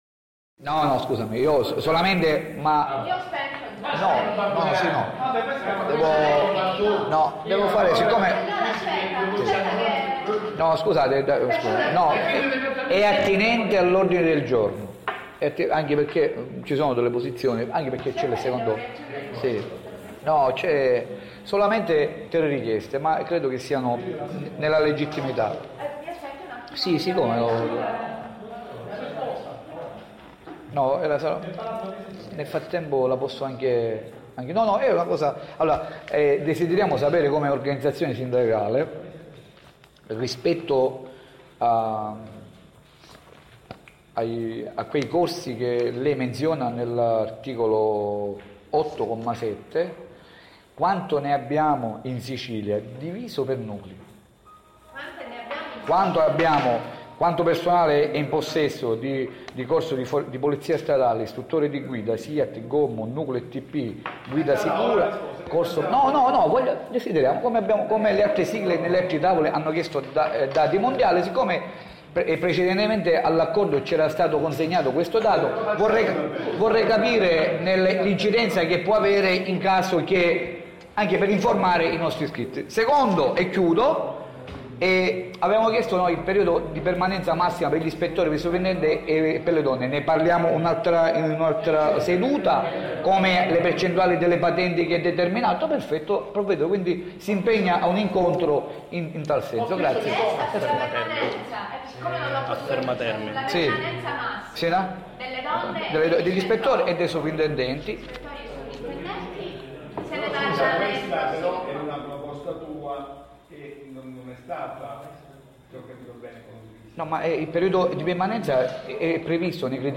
PRAP SICILIA: CRITERI MOBILITA' NUCLEI TRADUZIONE E PIANTONAMENTI - INTERVENTO DEL SEGRETARIO GENERALE DELLA SICILIA
PRAP SICILIA: ESAME CONGIUNTO 13 NOVEMBRE MODIFICA CRITERI MOBILITA' NUCLEI TRADUZIONE E PIANTONAMENTI DELLA SICILIA